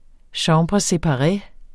Udtale [ ˌɕɑ̃bʁə sepɑˈʁε ]